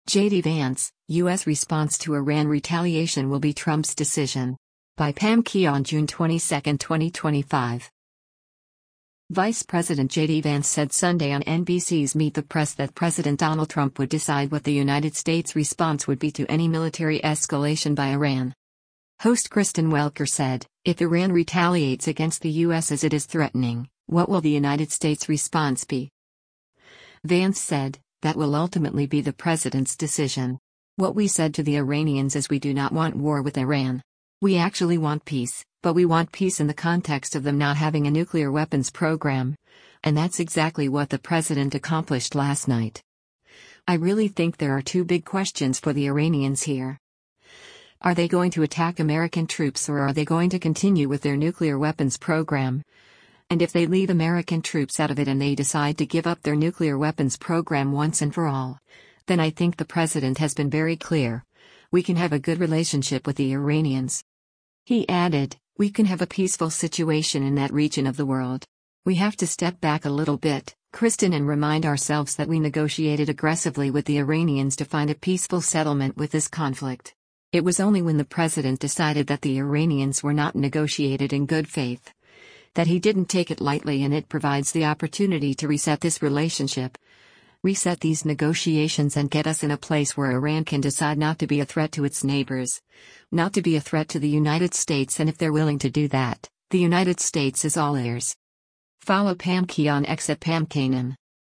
Vice President JD Vance said Sunday on NBC’s “Meet the Press” that President Donald Trump would decide what the United States’ response would be to any military escalation by Iran.
Host Kristen Welker said, “If Iran retaliates against the U.S. as it is threatening, what will the United States’ response be?”